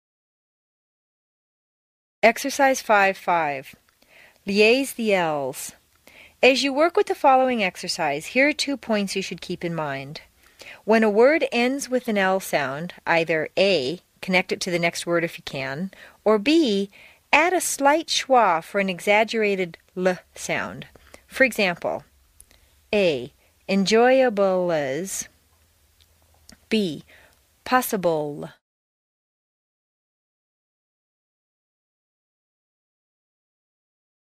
美式英语正音训练第72期:练习5(5) 听力文件下载—在线英语听力室
在线英语听力室美式英语正音训练第72期:练习5(5)的听力文件下载,详细解析美式语音语调，讲解美式发音的阶梯性语调训练方法，全方位了解美式发音的技巧与方法，练就一口纯正的美式发音！